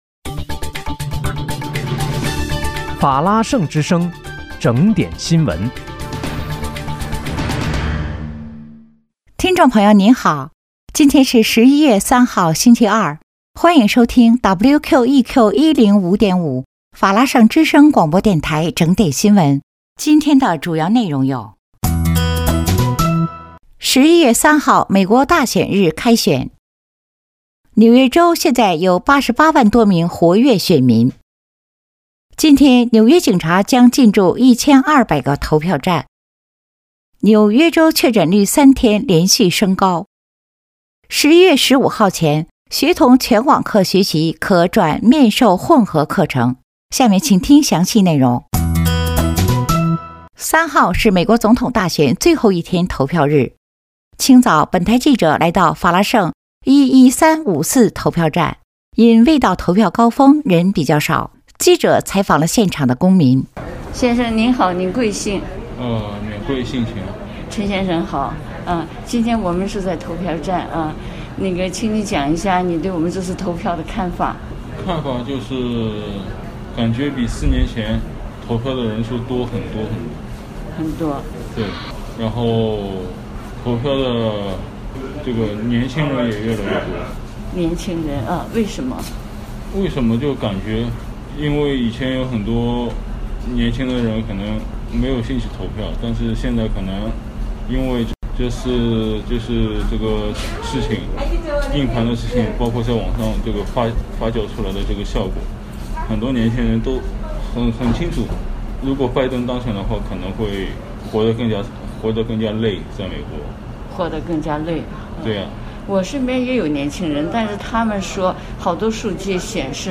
11月3日（星期二）纽约整点新闻